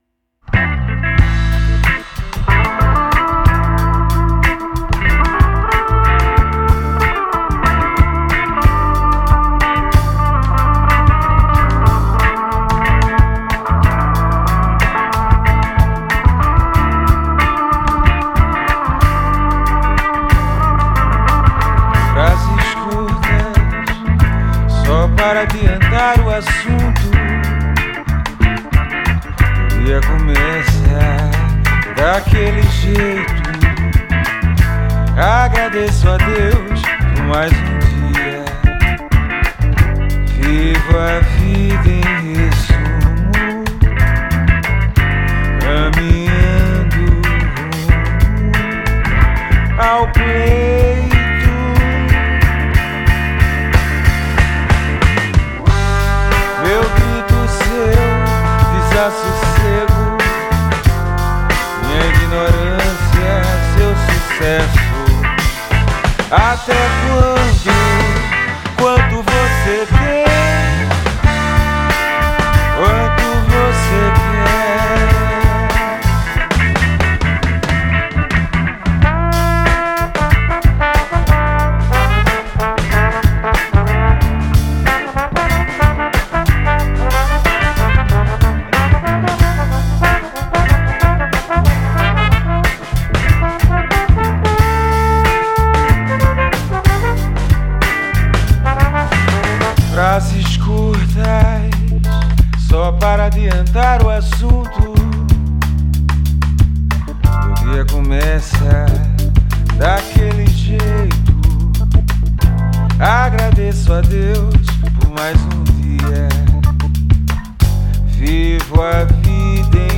EstiloAlternativo